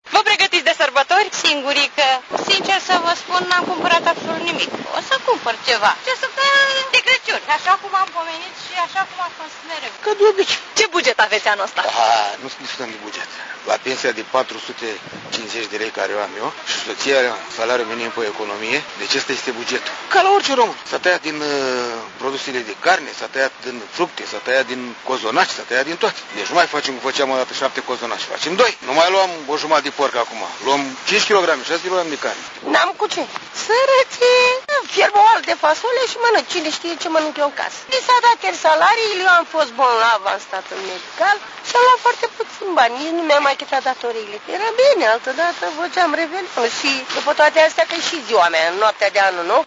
Un reporter Radio Campus a stat de vorbă cu câţiva dintre trecătorii prin Piaţa Revoluţiei şi a aflat că ialomiţenii încearcă din puţinul lor să aducă în casă şi la masă spiritul sărbătorilor, chiar dacă lista de cumpărături se împuţinează de la an la an, iar veniturile scad, în timp ce costurile lunare cresc:
audio-SONDAJ-SLOBOZIA.mp3